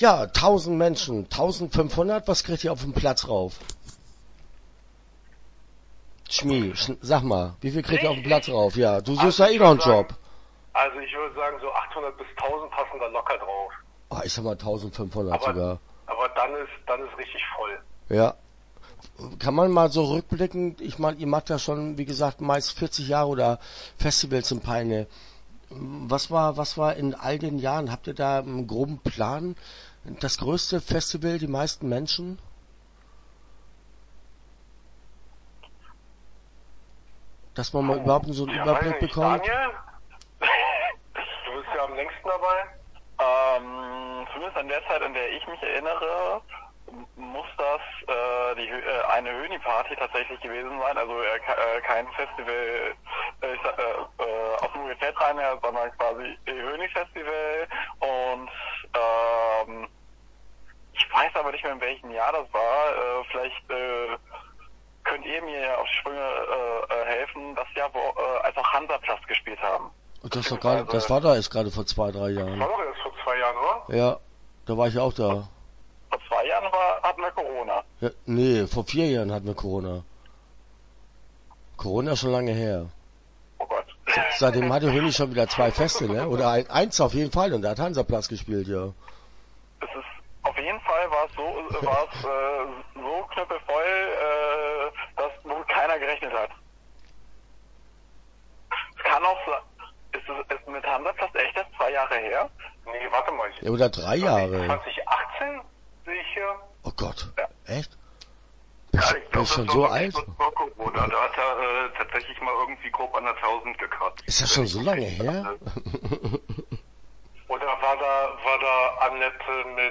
Refuse-Festival - Interview Teil 1 (11:34)